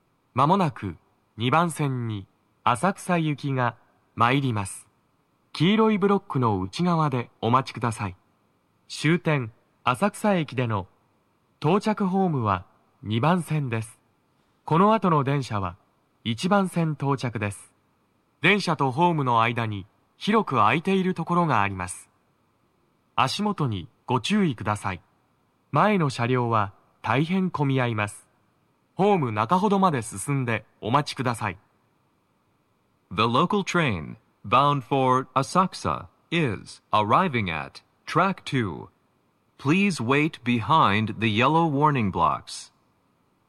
鳴動は、やや遅めです。
2番線 浅草方面 接近放送 【男声
接近放送4